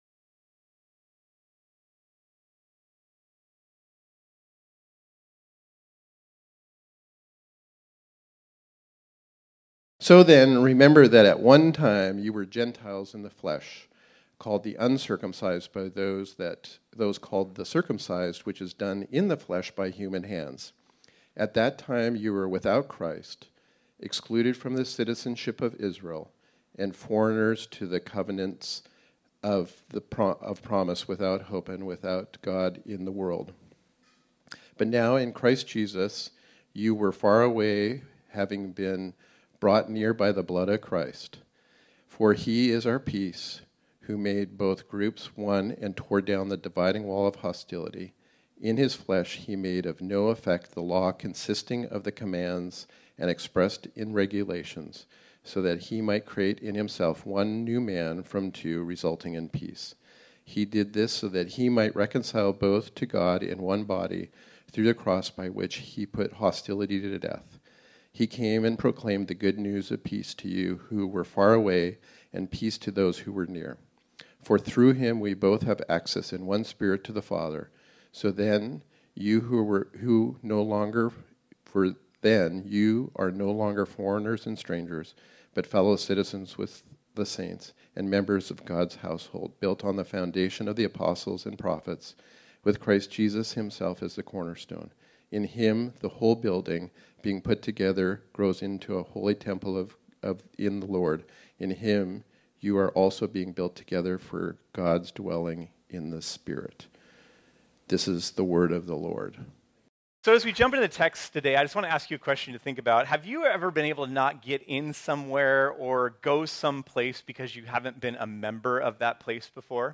This sermon was originally preached on Sunday, October 1, 2023.